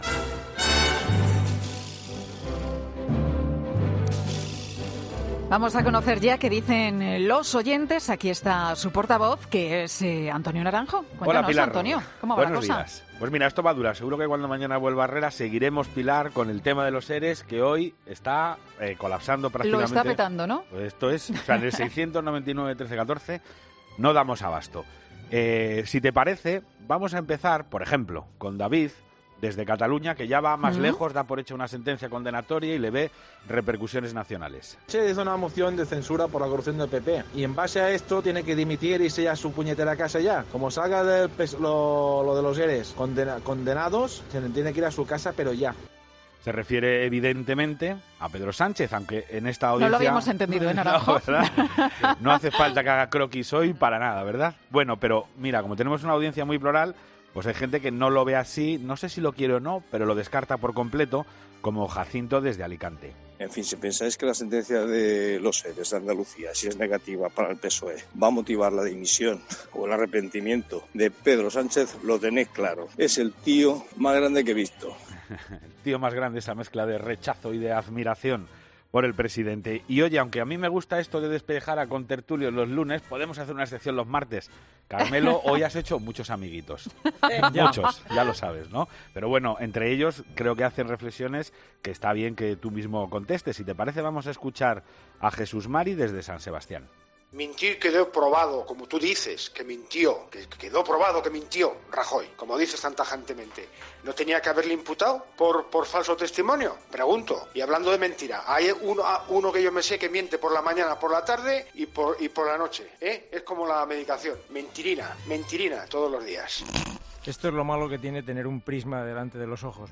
Avalancha de mensajes en el contestador de ‘Herrera en Cope’ con el tema del día: las posibles consecuencias sobre la sentencia de los ERE.